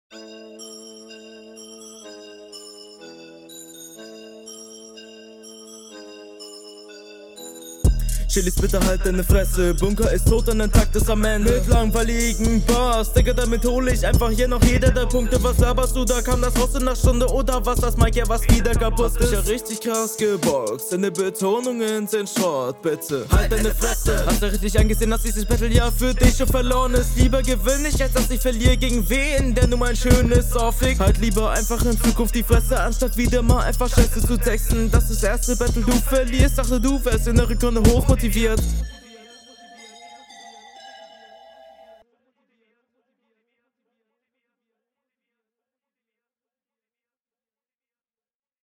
Teilweise etwas unsicher geflowed, aber etwas besser als dein Gegner.
Runde kurz und knackig, Verständlichkeit und Mixing ausbaufähig aber sehr guter Flow mit nicen kleinen …